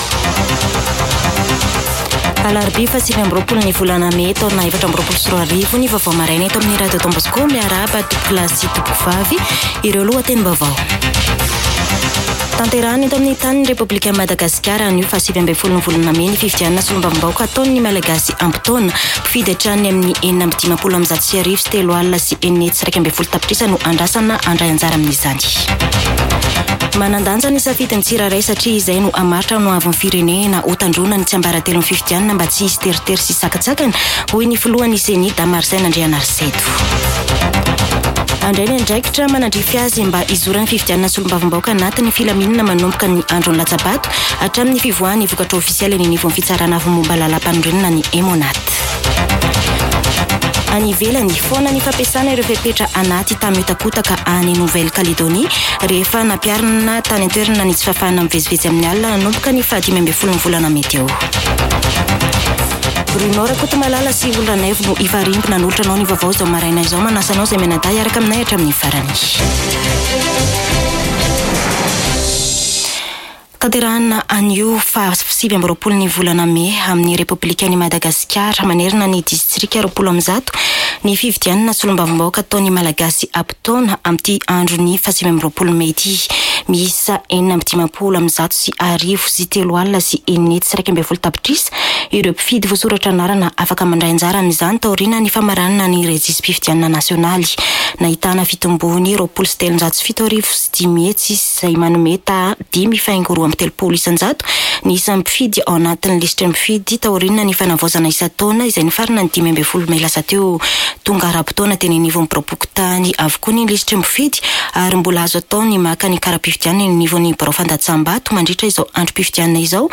[Vaovao maraina] Alarobia 29 mey 2024